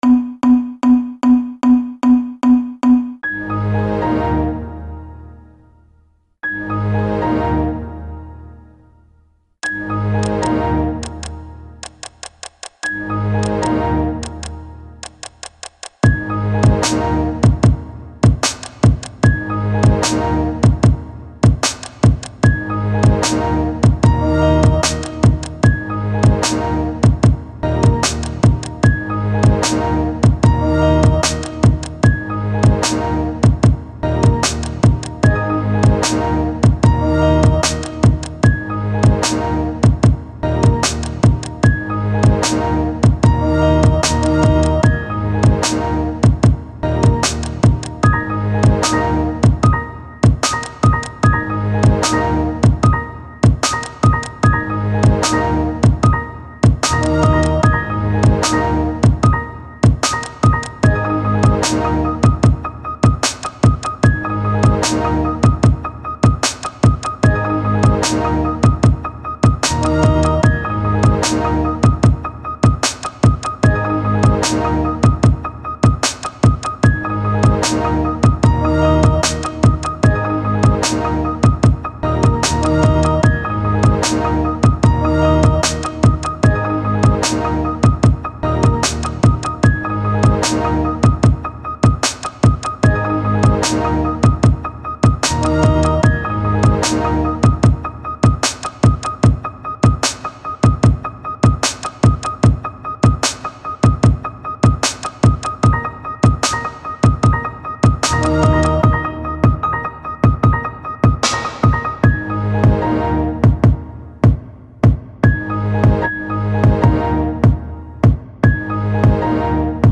윈도우 효과음으로 만든 음악입니다.
4 Thumb Up 미디어 듣기 킥,스네어 빼고는 전부 윈도우 효과음으로 만들어 봤네여 4 Scrap This!